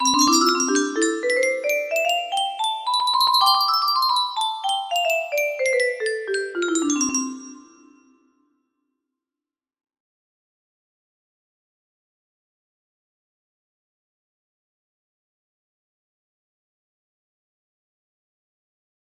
Test music box melody